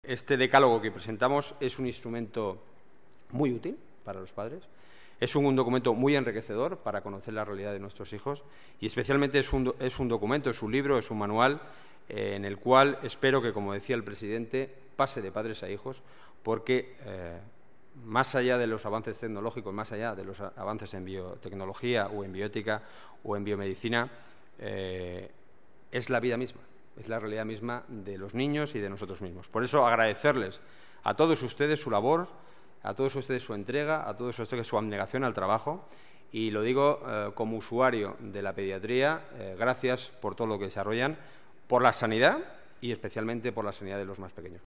Nueva ventana:Declaraciones del delegado de Seguridad y Emergencias, Enrique Núñez: Decálogo Infantil